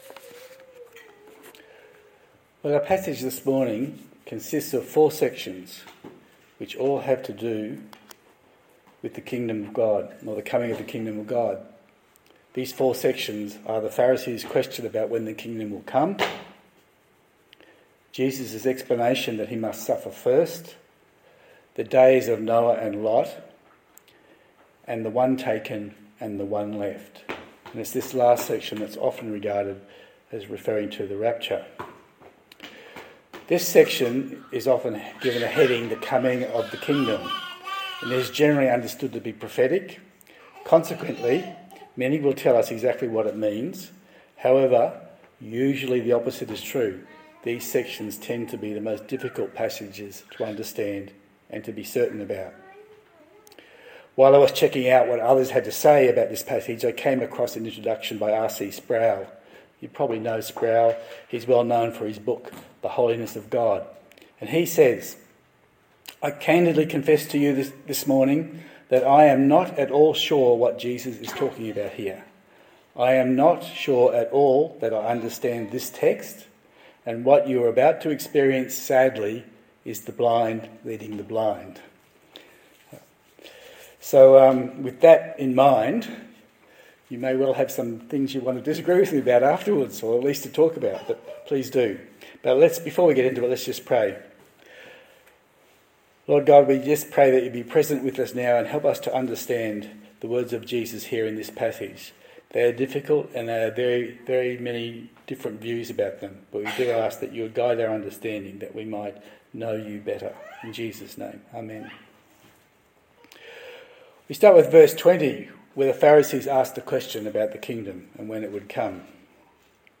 Luke Passage: Luke 17:20-37 Service Type: Sunday Service